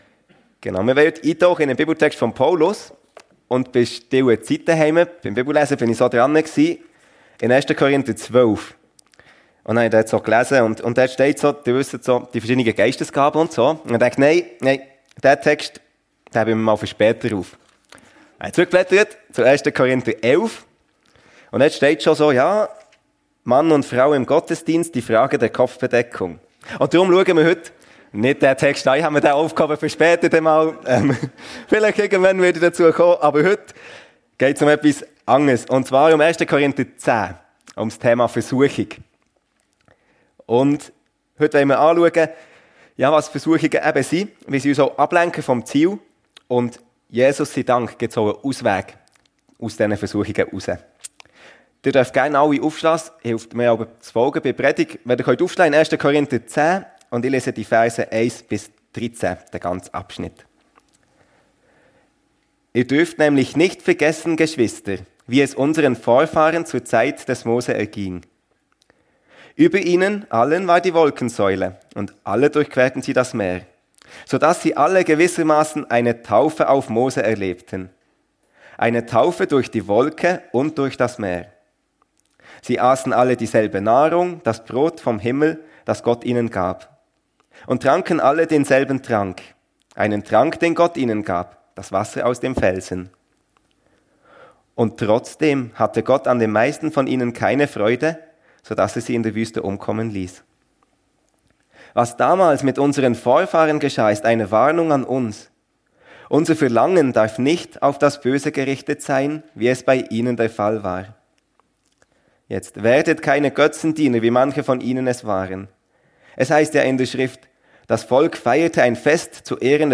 Versuchung - Jesus sei Dank gibt es einen Ausweg ~ FEG Sumiswald - Predigten Podcast